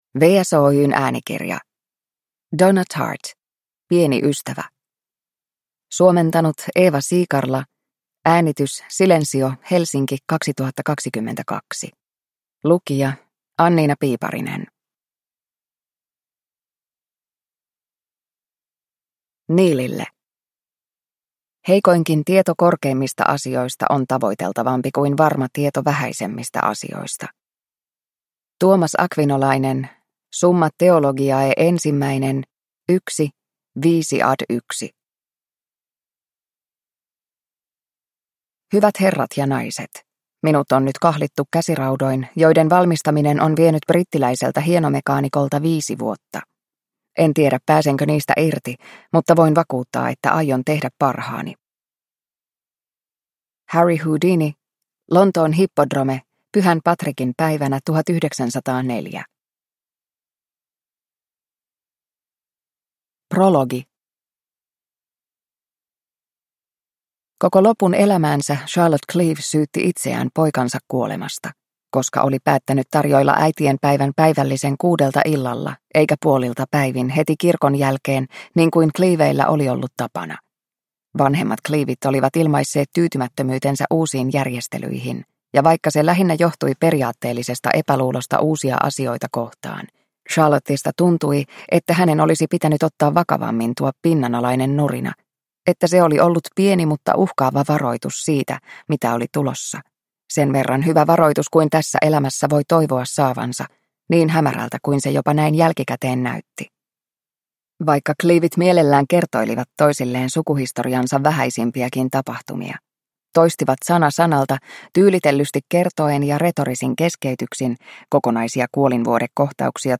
Pieni ystävä – Ljudbok – Laddas ner